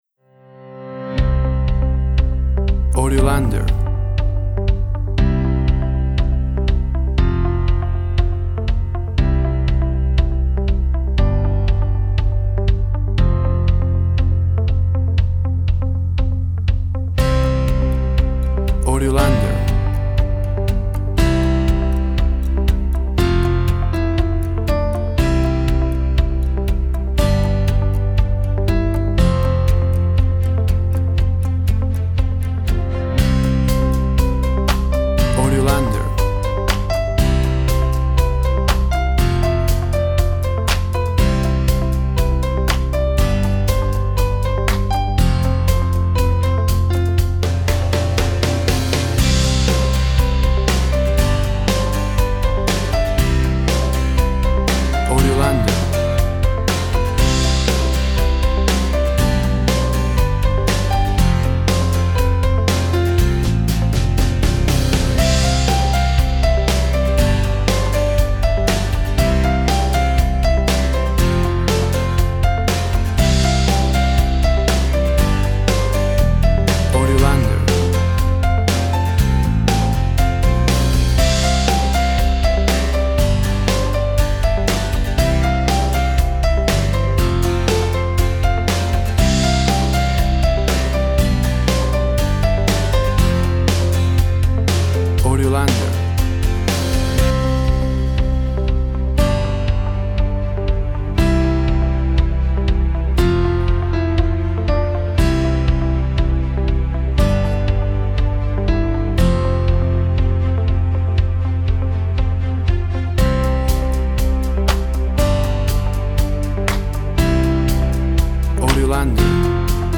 WAV Sample Rate 24-Bit Stereo, 44.1 kHz
Tempo (BPM) 120